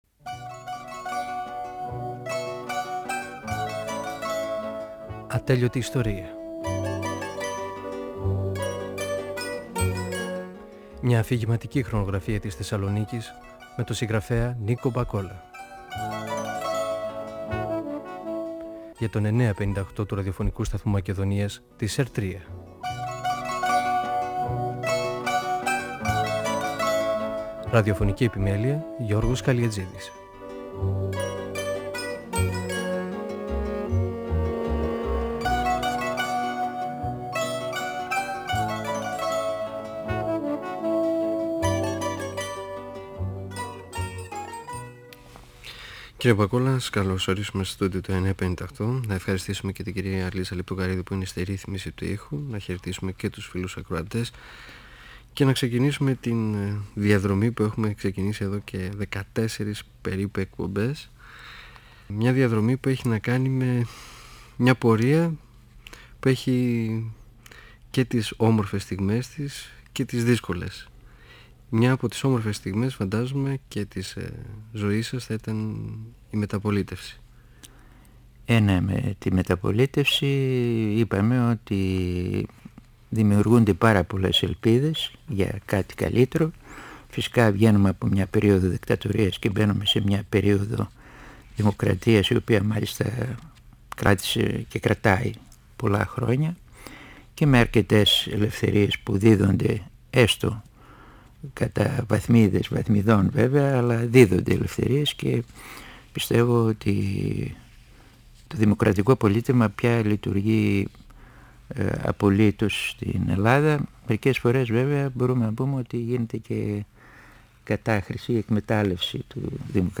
Ο πεζογράφος Νίκος Μπακόλας (1927-1999) μιλά γιατην περίοδο της μεταπολίτευσης.